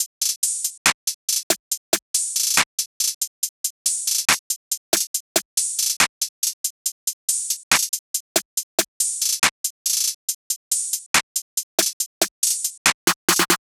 SOUTHSIDE_beat_loop_herb_top_01_140.wav